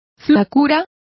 Complete with pronunciation of the translation of thinness.